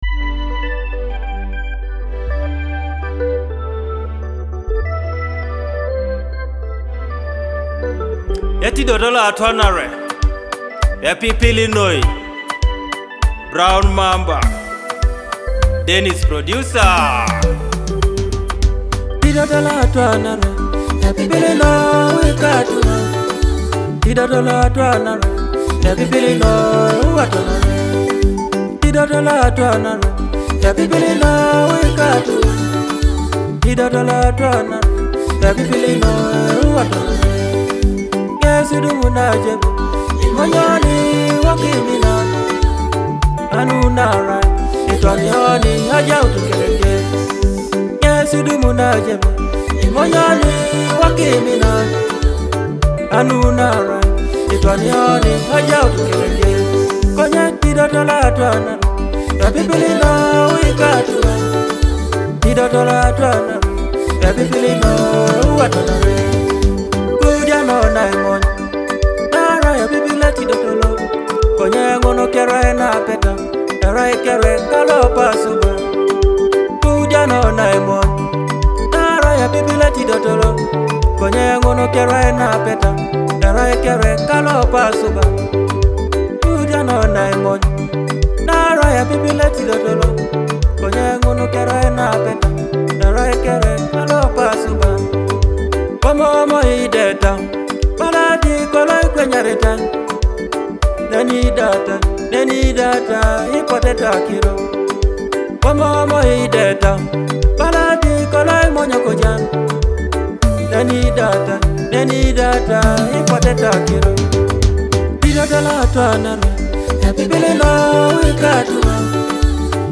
a bold and energetic track